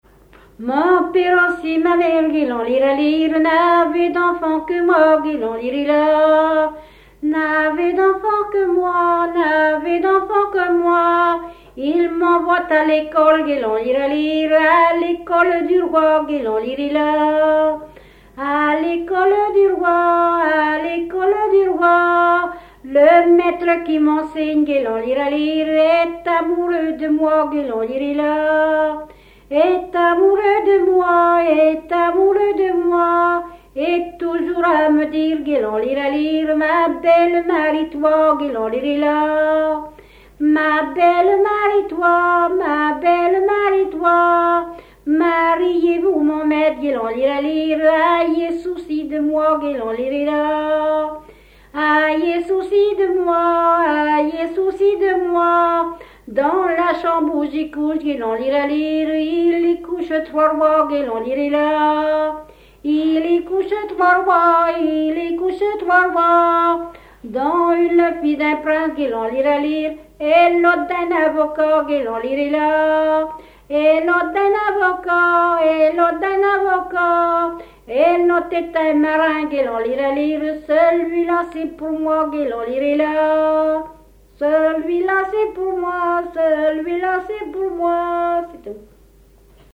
Localisation Barbâtre (Plus d'informations sur Wikipedia)
Fonction d'après l'analyste danse : ronde ;
Genre laisse
Catégorie Pièce musicale inédite